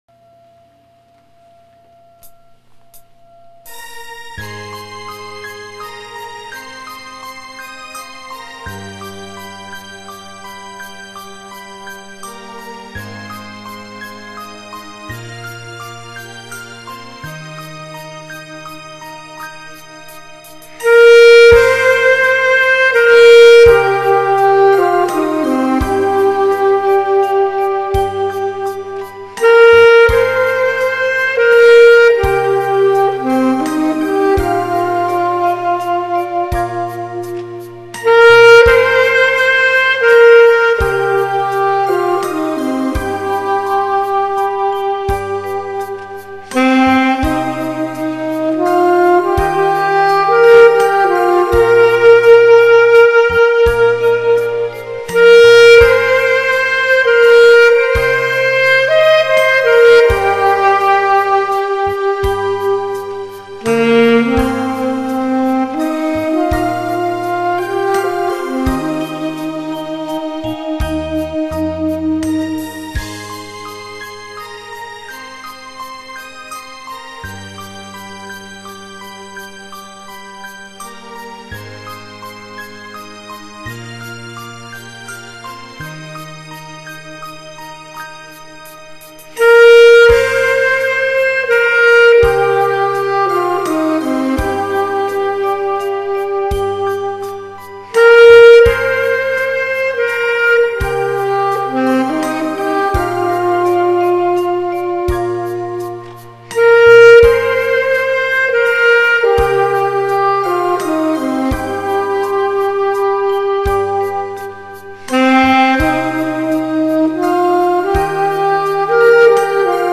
봄처녀---앨토